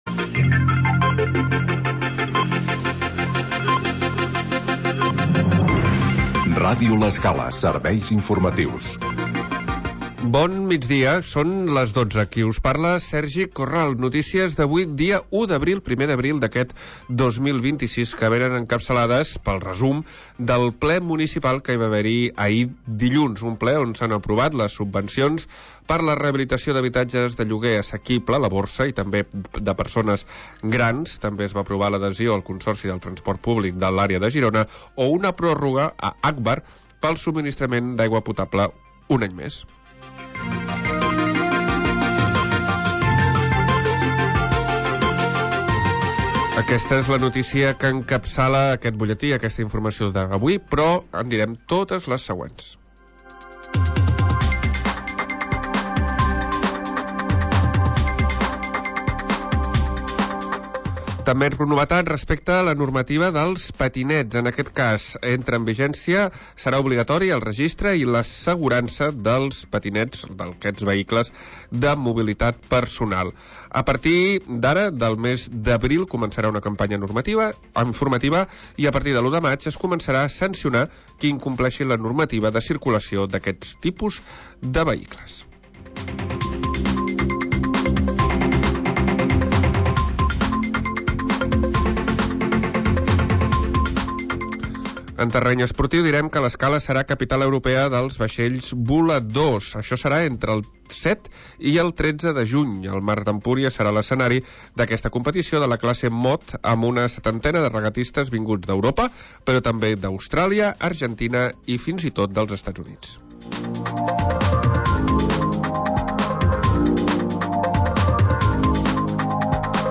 Magazin d'entretiment per acompanyar el migdiaompanyar